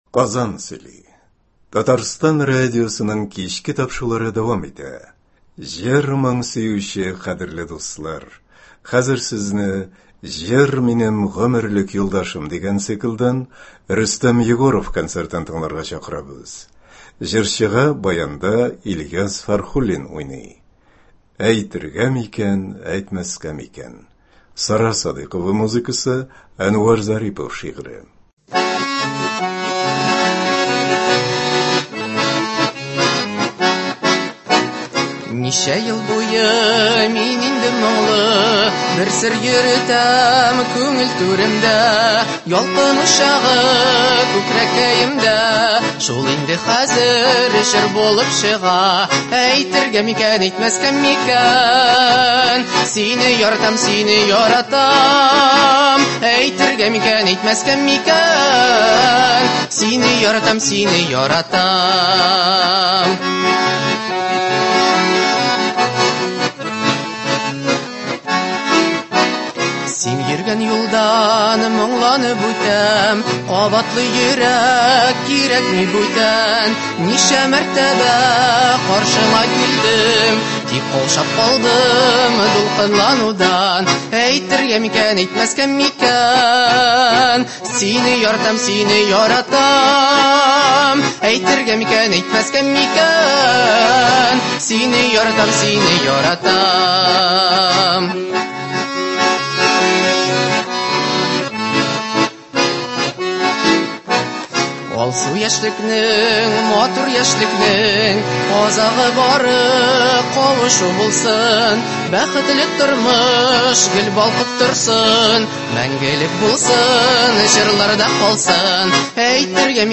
яшь җырчы
баянда